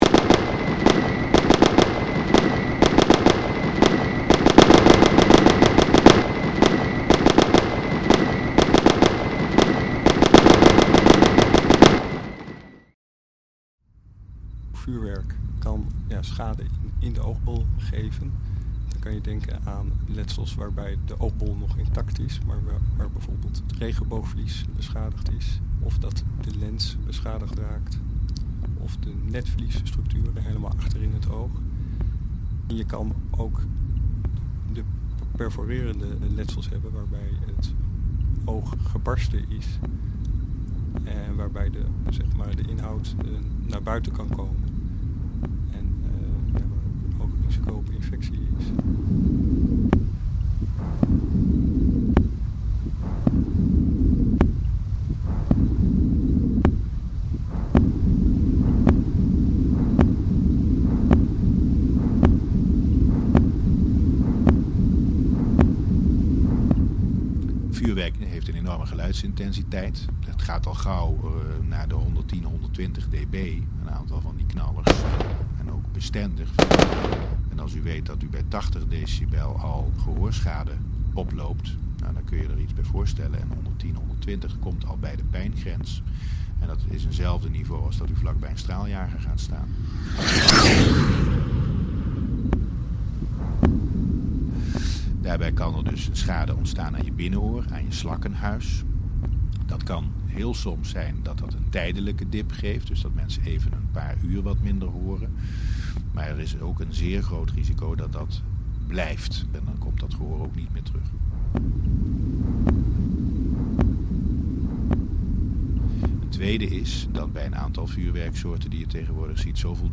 Audio portrait on eye and ear protection during New Years Eve for Edge 2.0 radio. Participative journalist interviews medical doctors involved with eye and ear trauma.